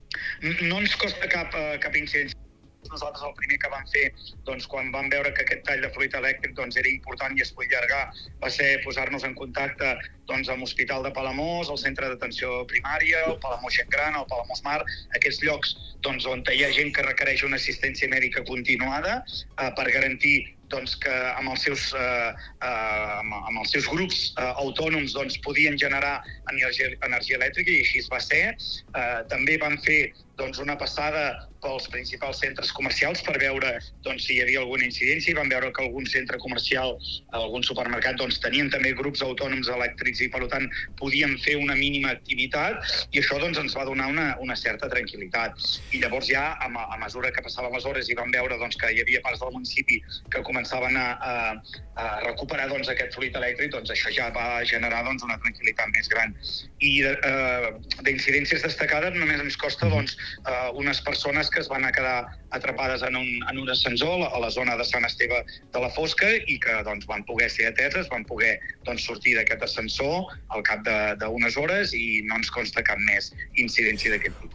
Al Supermatí hem fet un programa especial per poder recollir els testimonis d'alcaldes, ciutadans i empresaris de la comarca per veure com van afrontar les hores sense llum d'aquest dilluns
A Palamós, l’alcalde del municipi, Lluís Puig, explicava durant el programa que la recuperació de la llum al municipi es va fer de manera progressiva fins a la mitjanit.